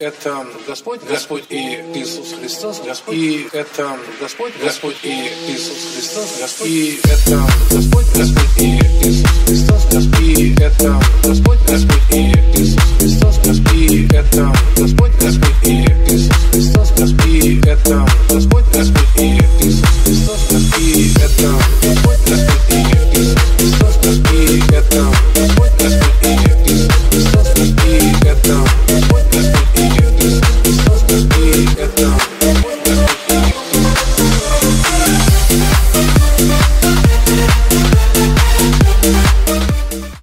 клубные # громкие